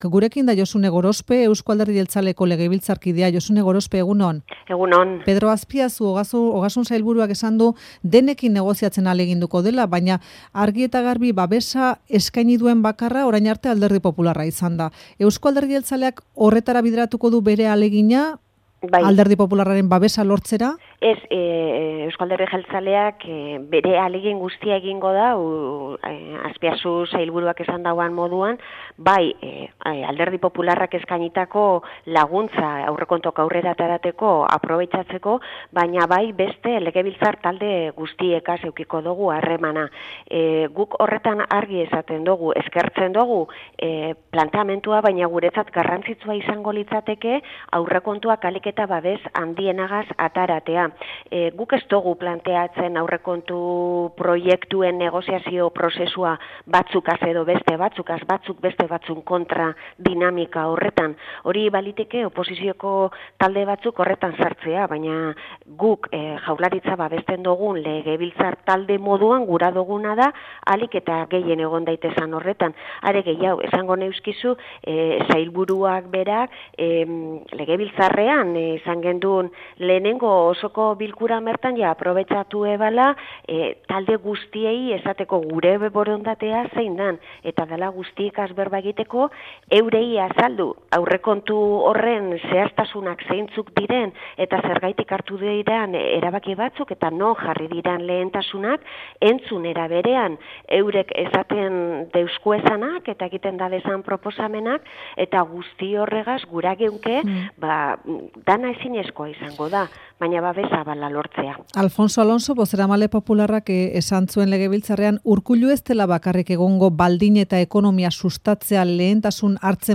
Audioa: Legebiltzarkide jeltzaleak aurrekontuen proposamenari buruz alderdi guztiekin eztabaidatuko dutela azaldu du Euskadi Irratiko Faktorian